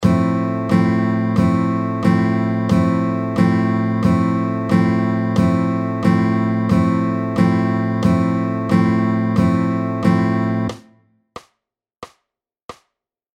EX6は４分音符のリズムです。
※エクササイズの際のコードストロークは全てダウンストロークで演奏しましょう。
EX６　Fコードで４分音符のリズムを弾いてみよう